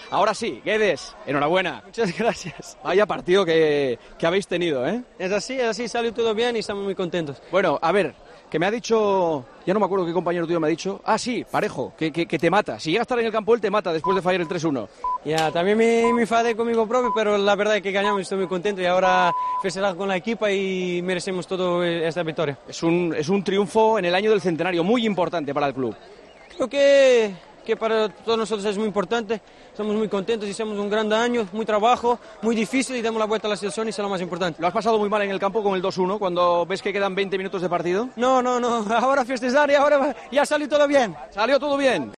El portugués atendió, muy contento, al micrófono de Juanma Castaño tras ganar la Copa del Rey.